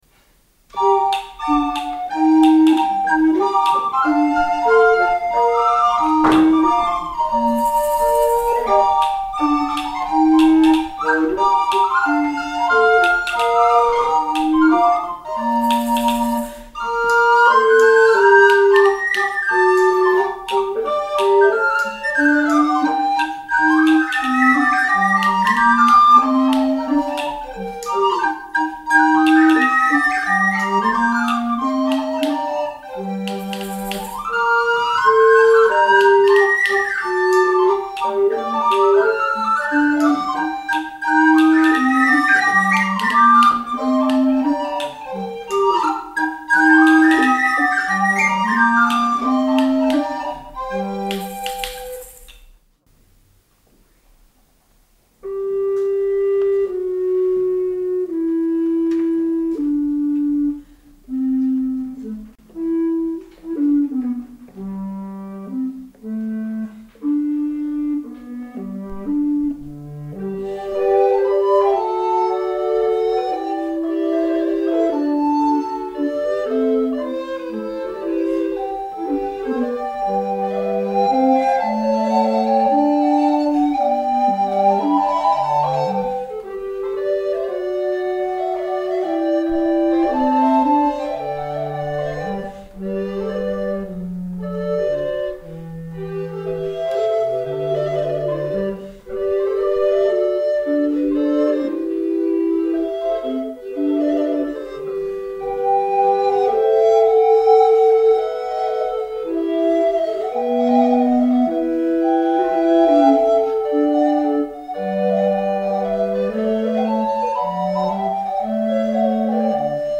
LIVE excerpt